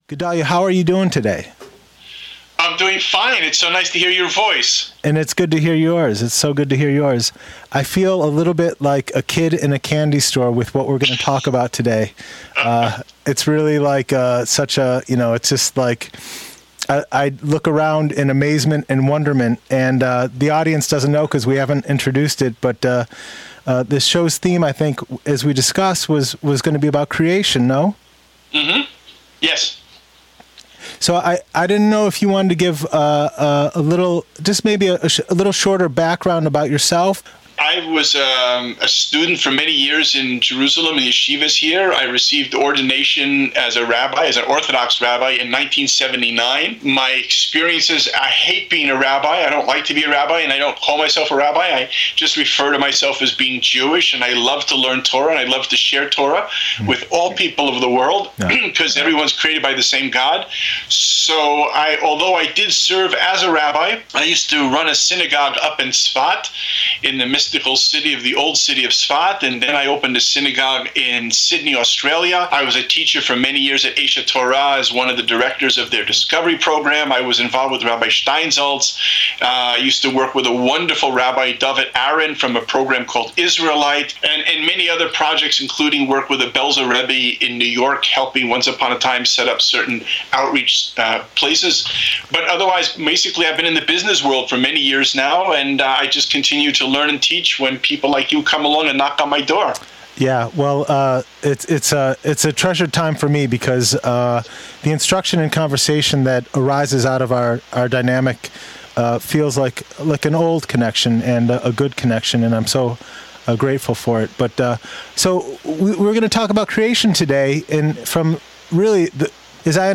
Part 1 'Creation' In Torah; The Four Rivers, Formation, Creation, and What Is Made, An Examination and Orientation to Existence: Conversation